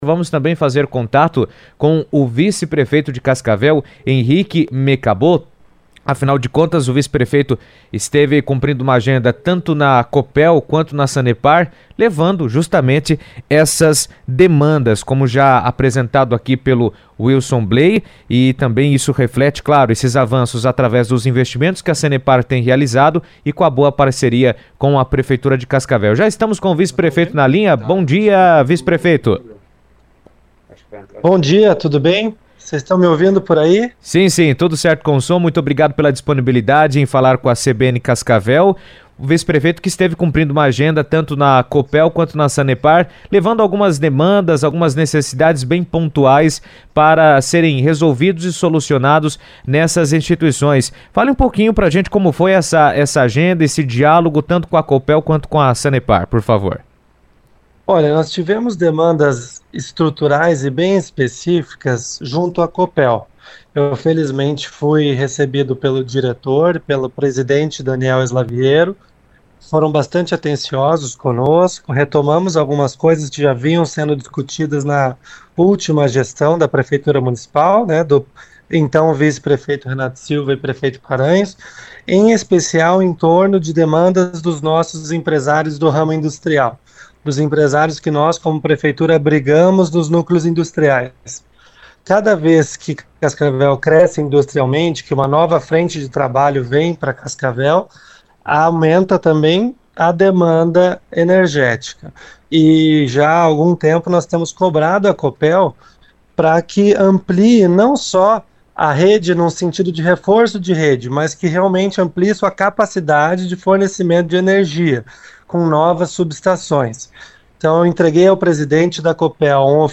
Por telefone, participou ao vivo na CBN e deu detalhes dessa visita.